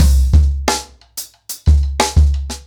Expositioning-90BPM.9.wav